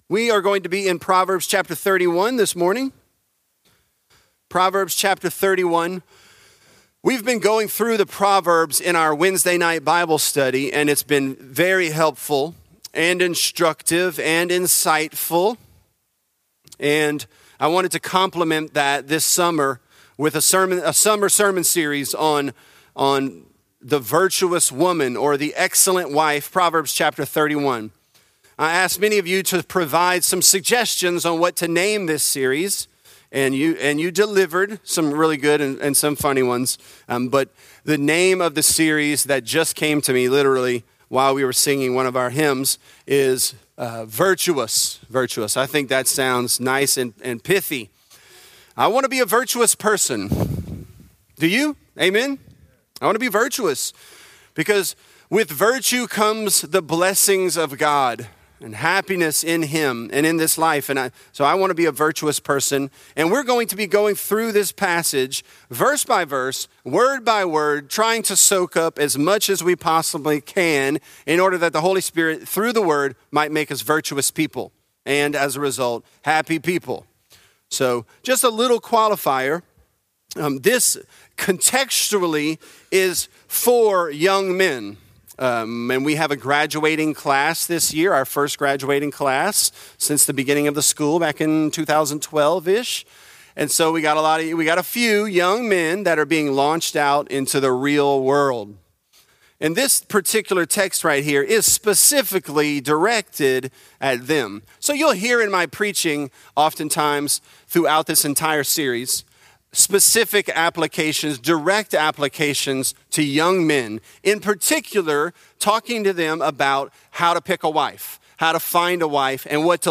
Virtuous: More Precious Than Rubies | Lafayette - Sermon (Proverbs 31)